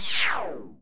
sounds_laser_04.ogg